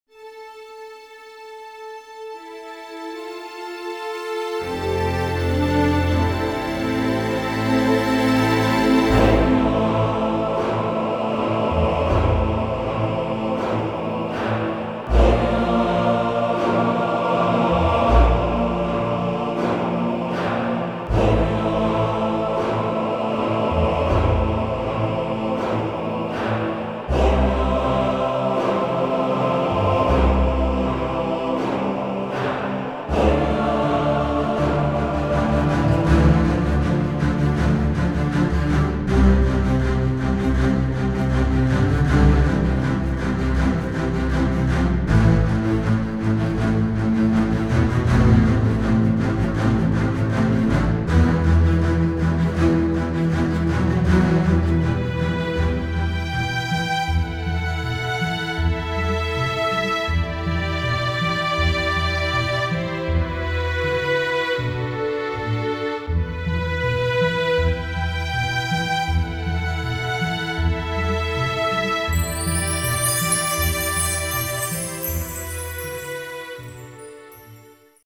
ΟΡΧΗΣΤΡΙΚΑ